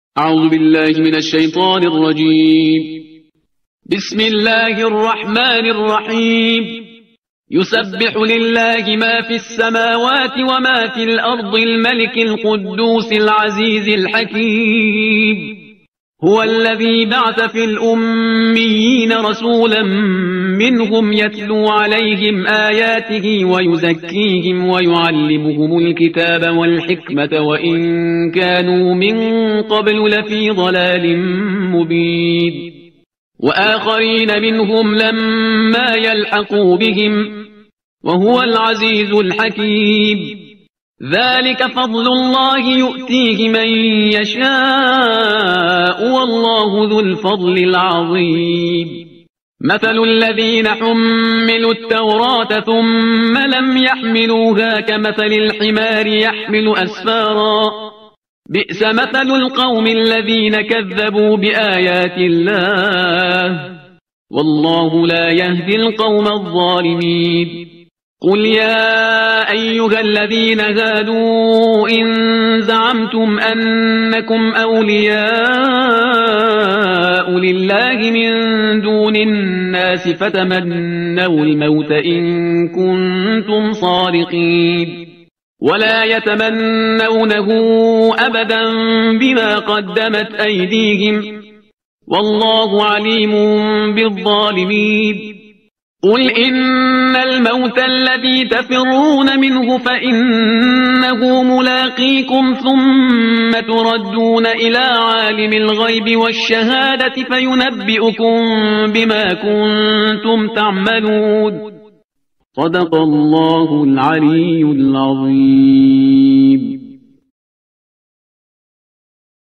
ترتیل صفحه 553 قرآن با صدای شهریار پرهیزگار